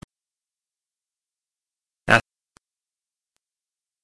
※音素から0.05秒〜0.06秒だけを切り出しています。
使用した音声はthe speech accent archiveenglish1です。
音声は文"Ask her to bring these things with her from the store"の単語"ask"の"a"でした。
この音声は、キャンディ・メソッドに慣れるまでは、一見、に聞こえるかも知れません。